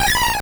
Cri de Granivol dans Pokémon Or et Argent.